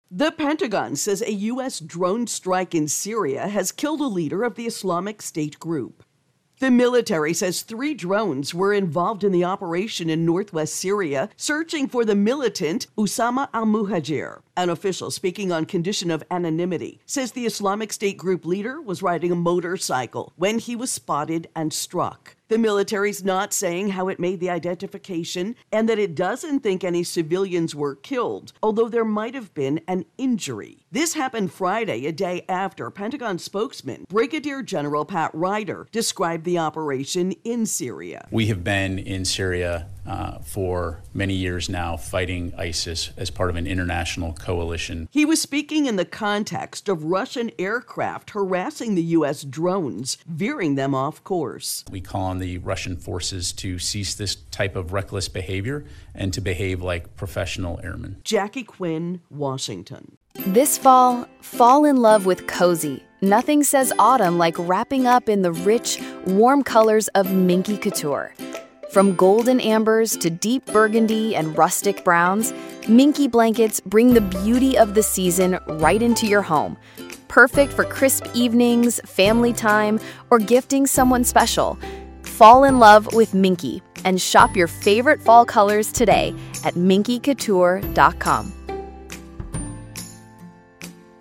reports on United States Syria Drone Strike ISIS.